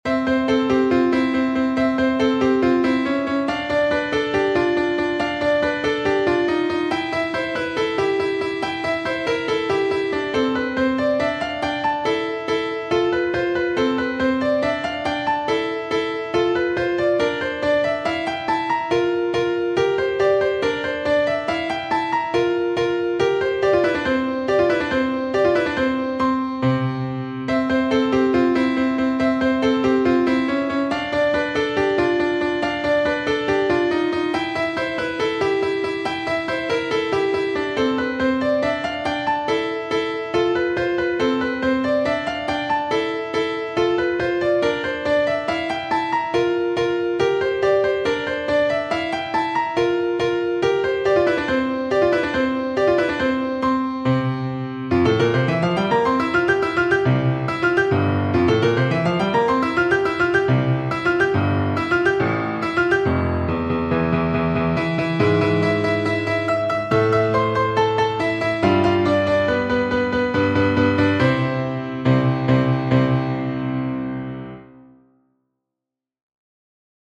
Piano 259 - Piano Music, Solo Keyboard - Young Composers Music Forum